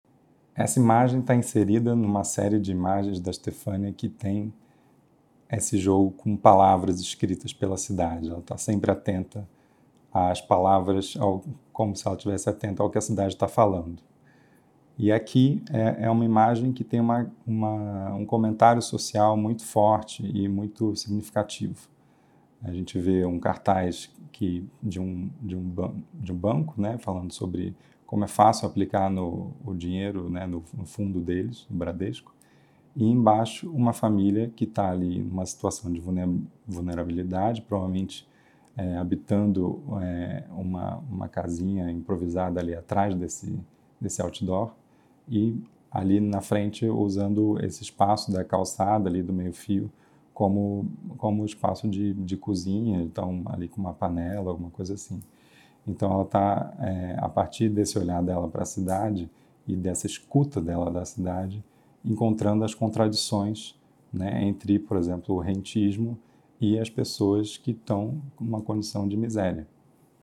Faixa 4 - Comentário da curadoria